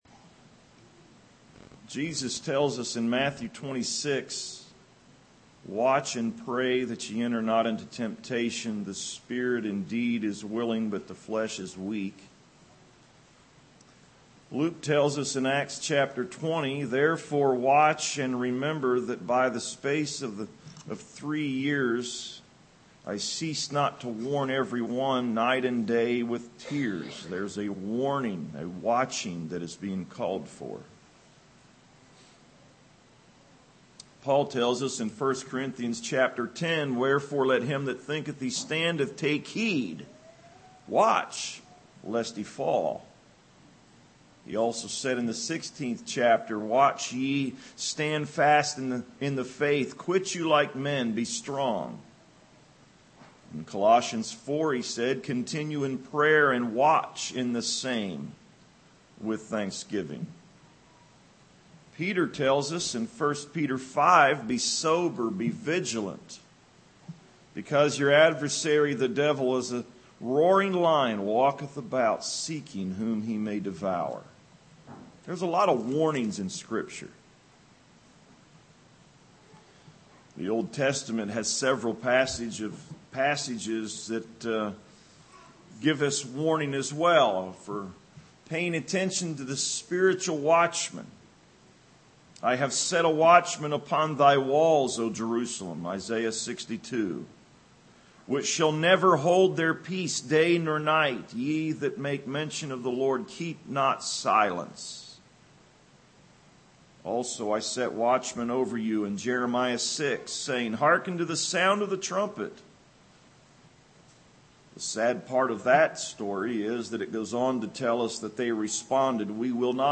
A short exhortation